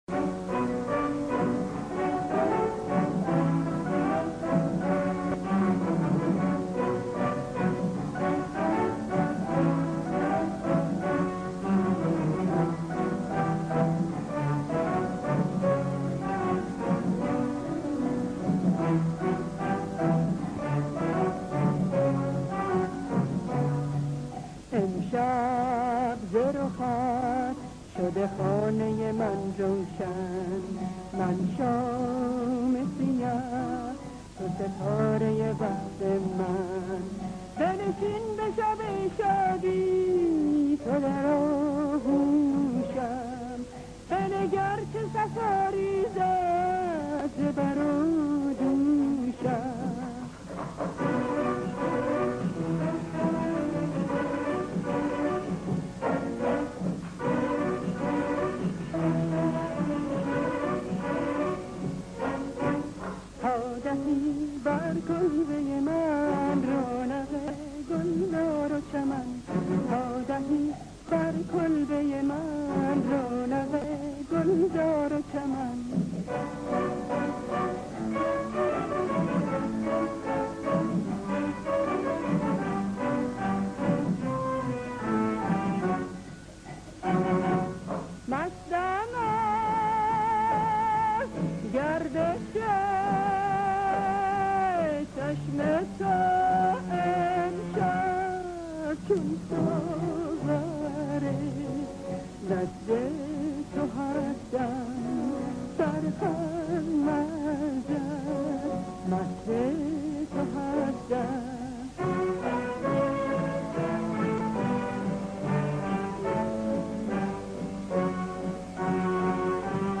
خوانندهٔ موسیقی اصیل ایرانی است .